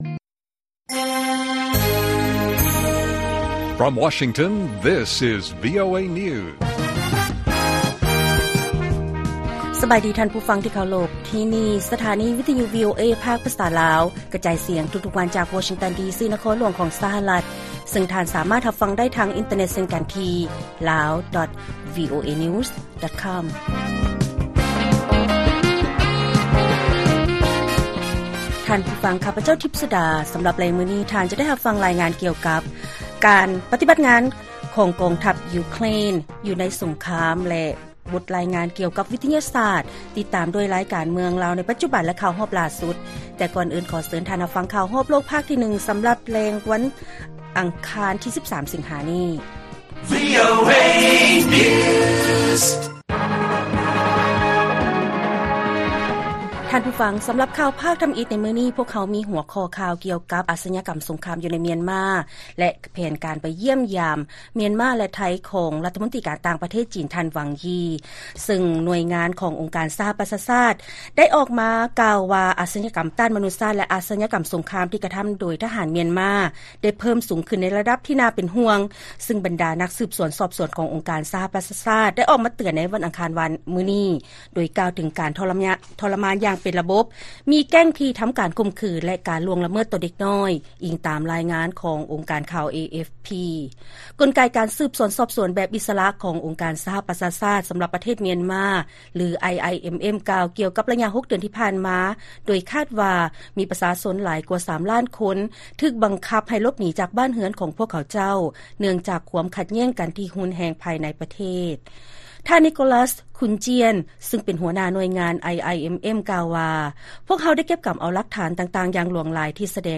ລາຍການກະຈາຍສຽງຂອງວີໂອເອ ລາວ: ການບຸກໂຈມຕີເຂົ້າໄປໃນຣັດເຊຍແບບບໍ່ໄດ້ຄາດຄິດຂອງຢູເຄຣນ ເຮັດໃຫ້ເກີດຄວາມກັງວົນ ກ່ຽວກັບຄວາມຮຸນແຮງທີ່ເພີ້ມຫຼາຍຂຶ້ນ.